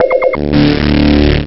suitchargeok1.wav